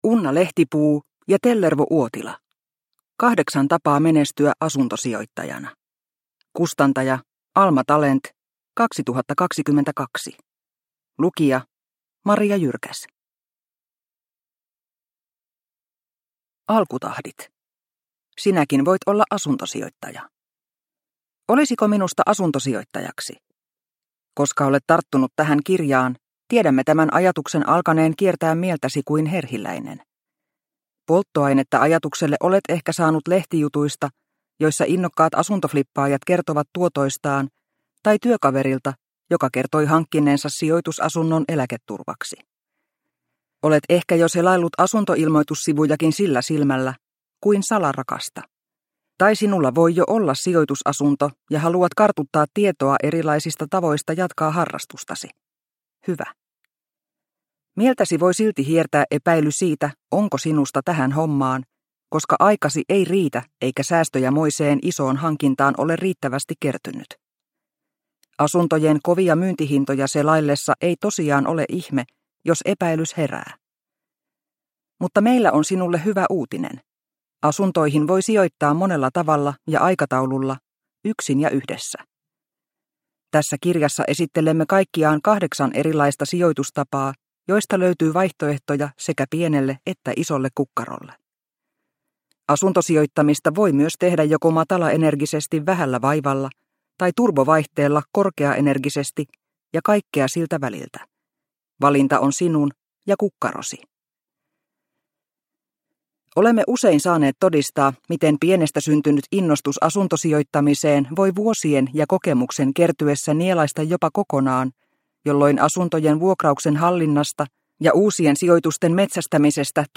8 tapaa menestyä asuntosijoittajana – Ljudbok – Laddas ner